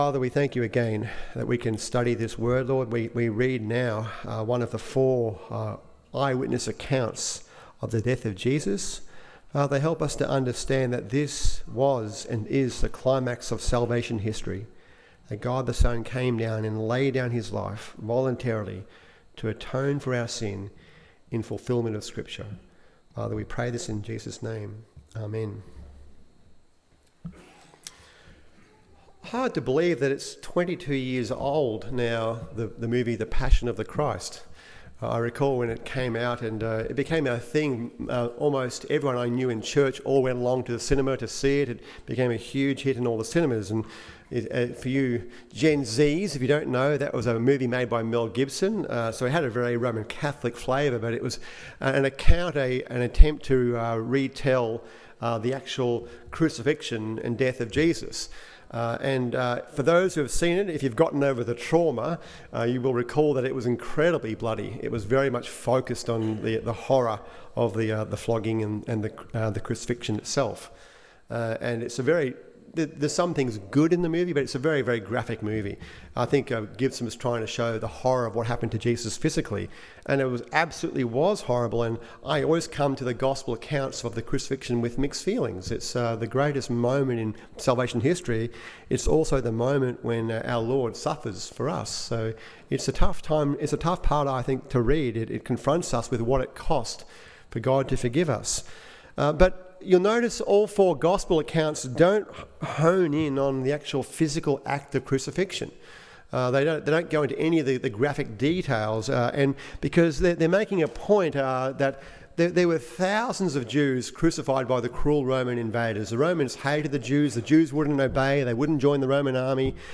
The Lamb Of God Takes Away The Sin Of The World Good Friday Service